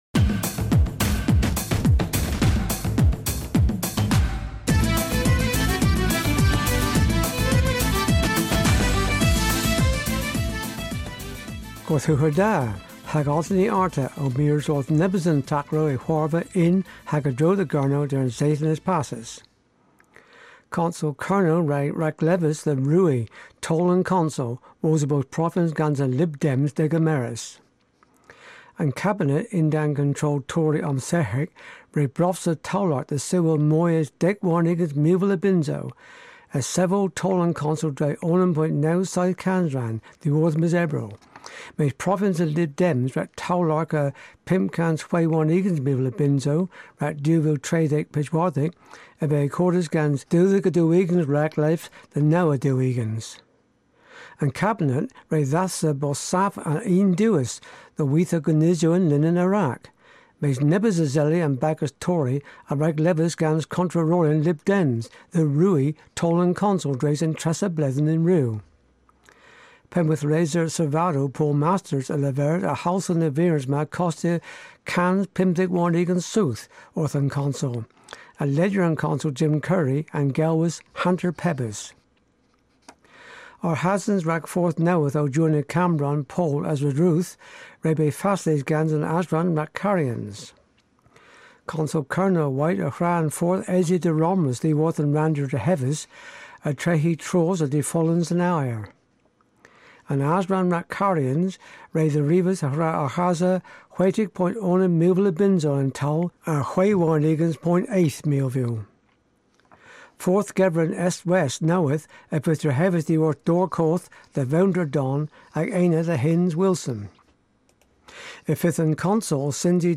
News in Cornish